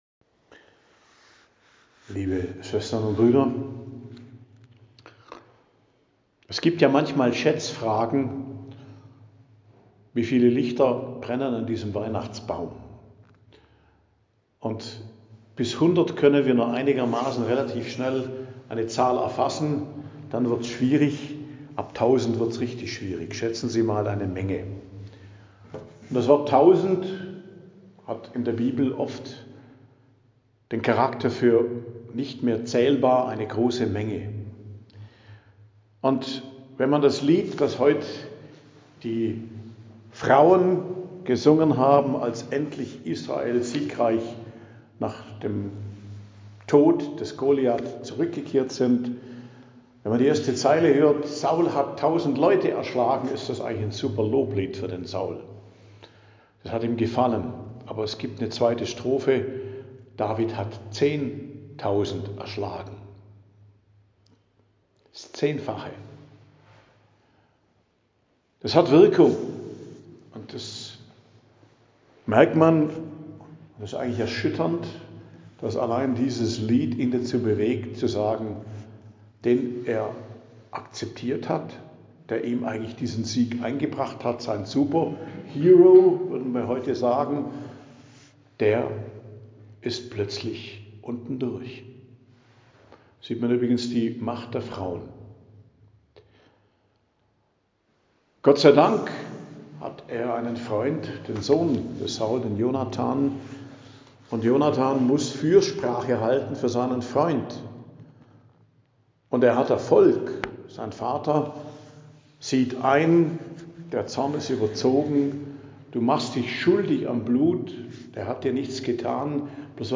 Predigt am Donnerstag der 2. Woche i.J., 22.01.2026